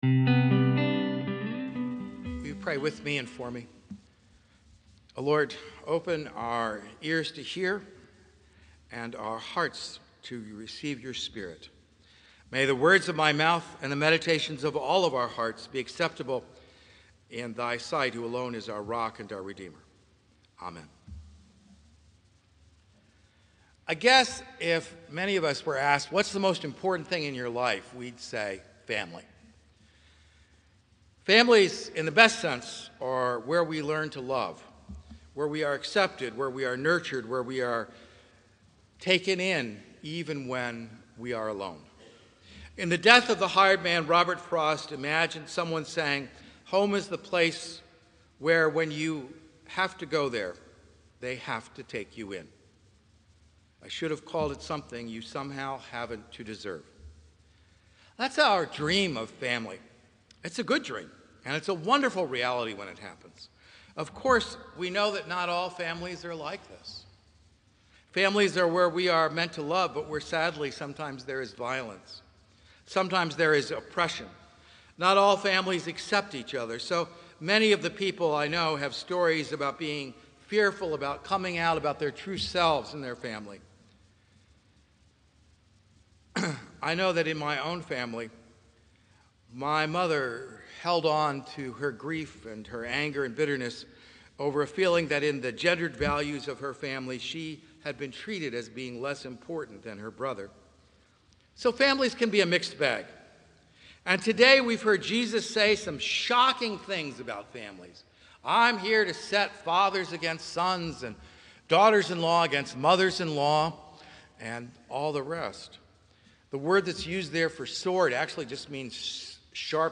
Click Here to Listen to the Sermon Being Preached